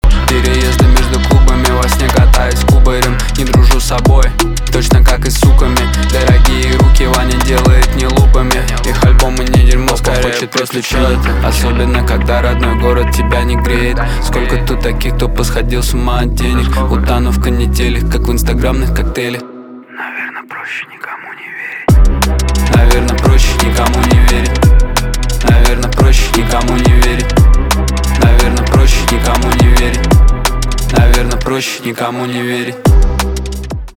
русский рэп , битовые , басы , качающие
жесткие , кайфовые , грустные